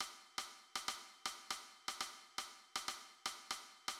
"Standard pattern" written in simple meter (4/4) and compound meter (12/8).
Bell patterns
The seven-stroke "standard bell pattern" is one of the most commonly used musical periods in sub-Saharan music.[27] The first three strokes of the bell are antecedent, and the remaining four strokes are consequent.
Standard_pattern_duple.mid.mp3